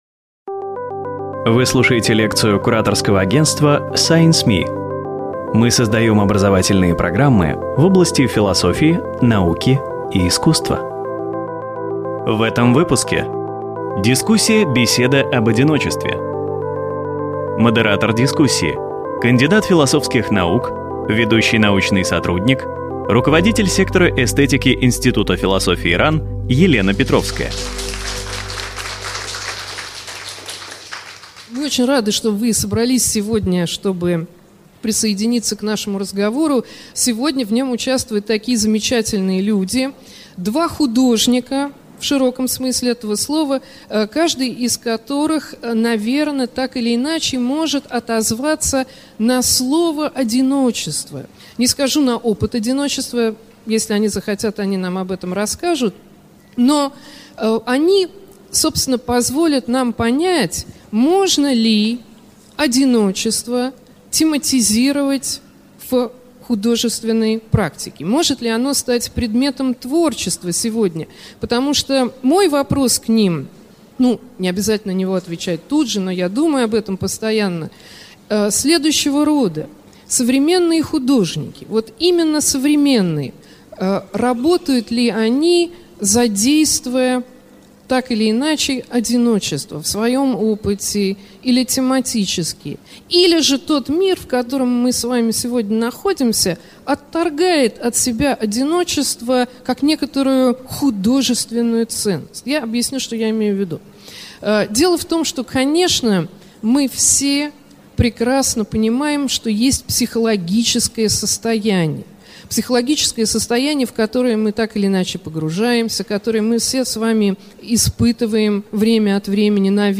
Аудиокнига Беседа об одиночестве | Библиотека аудиокниг